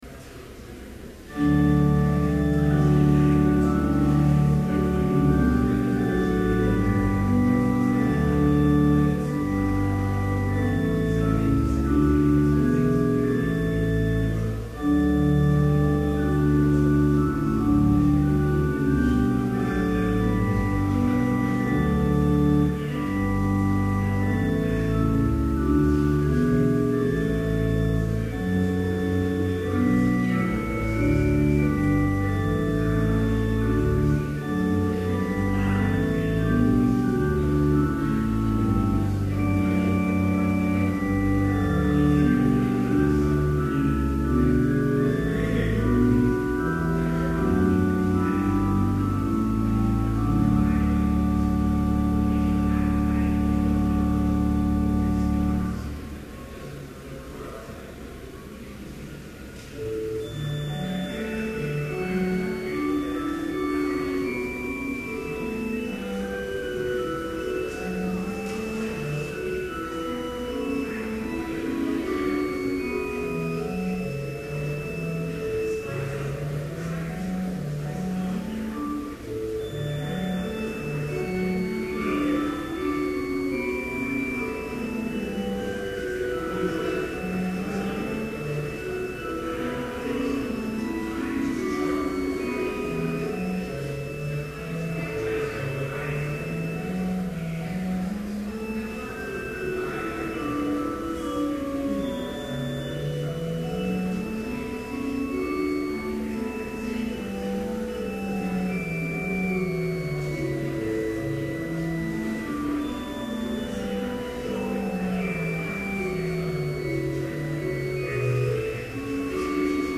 Chapel worship service held on November 18, 2011, BLC Trinity Chapel, Mankato, Minnesota, (audio available)
Complete service audio for Chapel - November 18, 2011
Benediction Postlude - Chapel Brass Scripture I Thessalonians 5:21-24 Test all things; hold fast what is good.